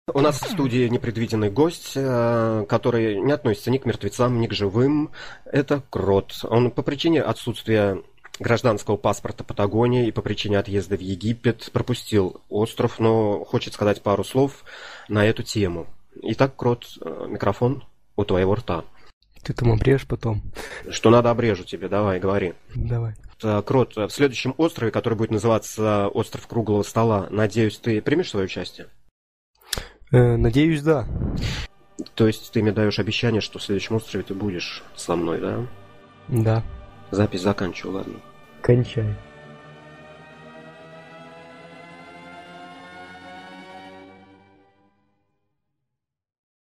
Интервью с лажей